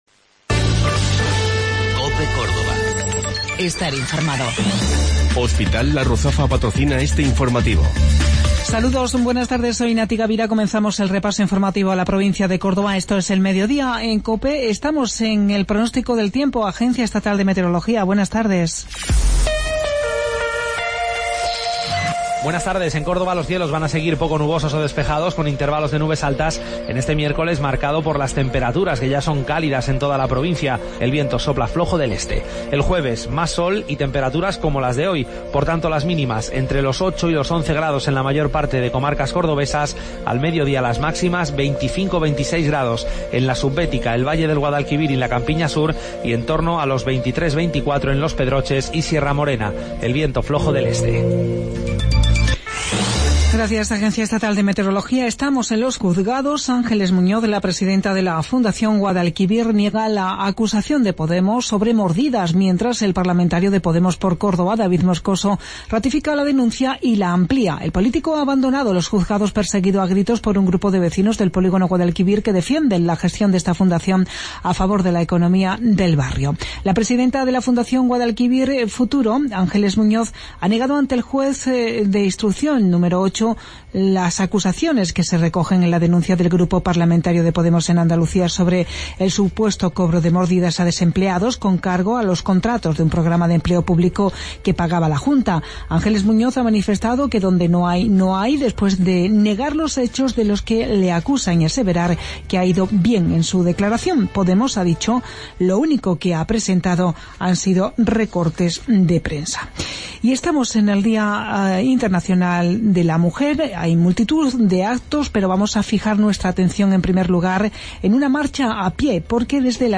Mediodía en Cope. Informativo local 8 de Marzo 2017